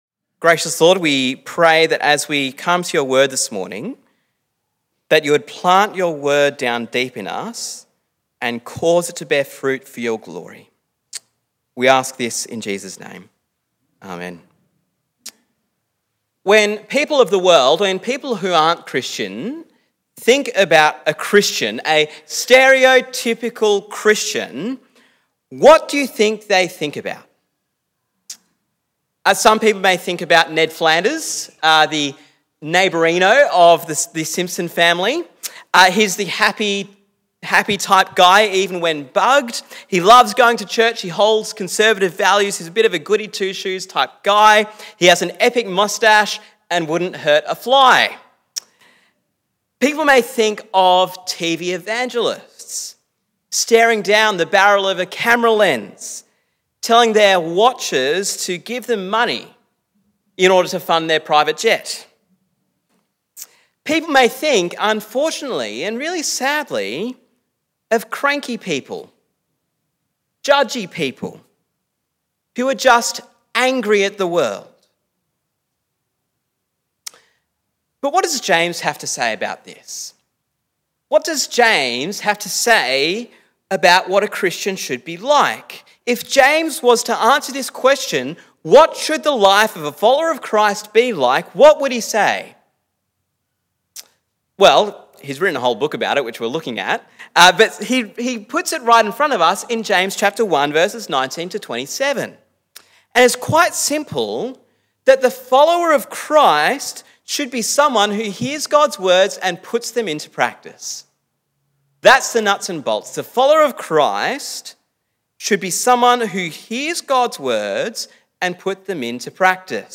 Sermon on James 1:19-27 - Real Faith Obeys